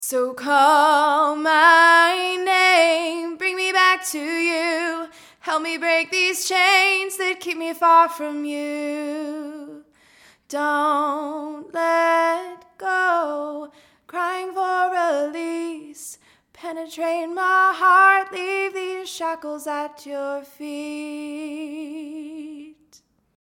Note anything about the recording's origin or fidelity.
I was trying out a couple preamps on this female vocalist using my at4047 mic. Two different takes, level-matched best I could.